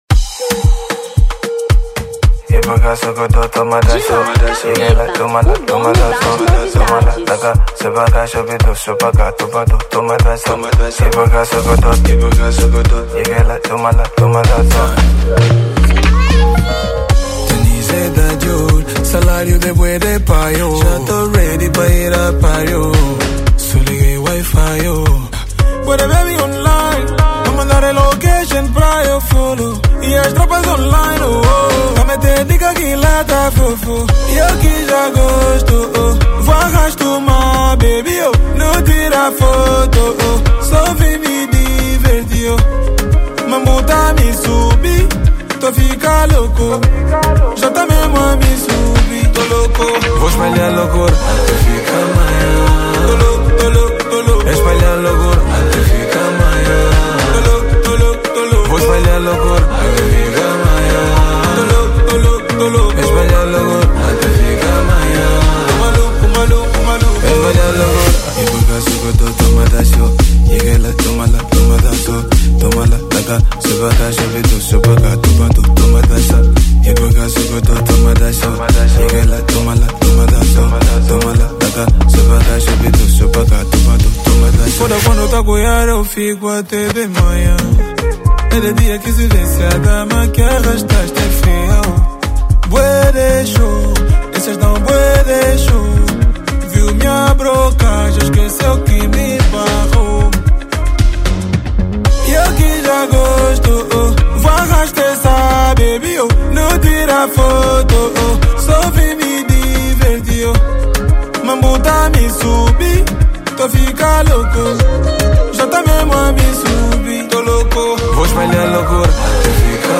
Amapiano 2024